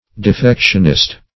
Defectionist \De*fec"tion*ist\, n. One who advocates or encourages defection.